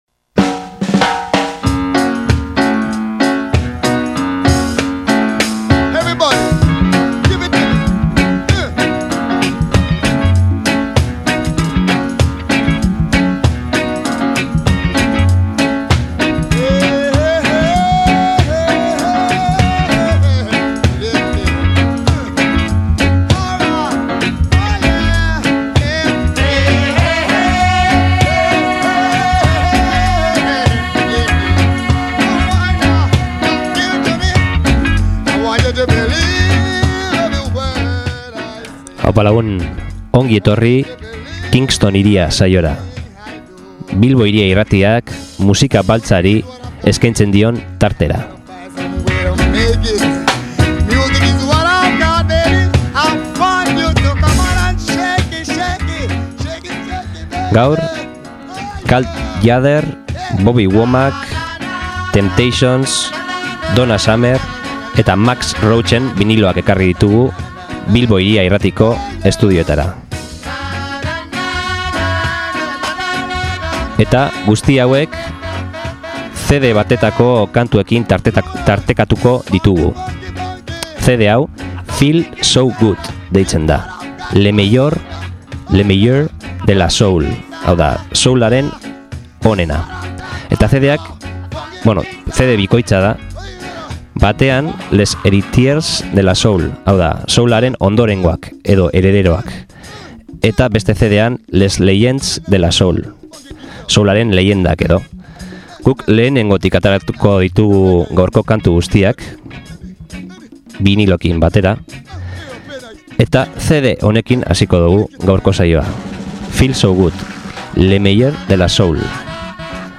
Soul, Jazz, Latin Jazz, Funk, Disco… musika beltza